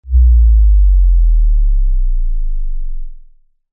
bassdrop2.mp3